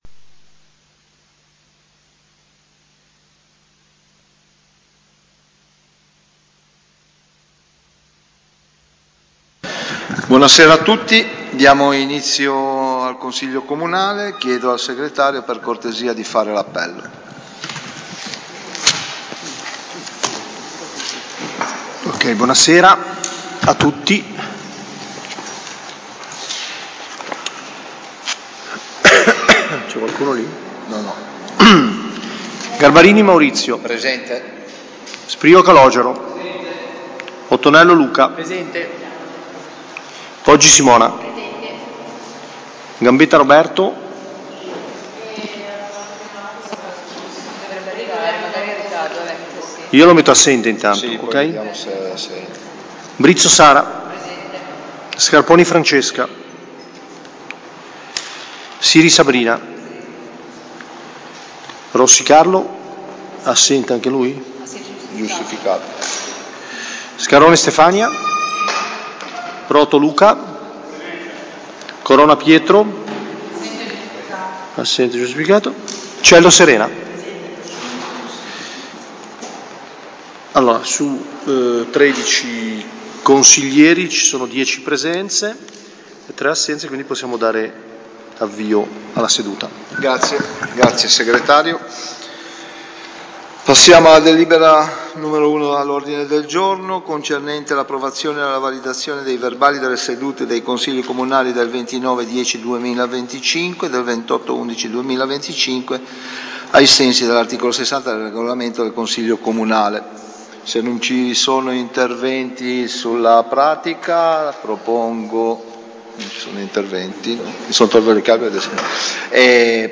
Seduta del Consiglio comunale martedì 30 dicembre 2025, alle 18.00, presso l'Auditorium comunale in via alla Massa.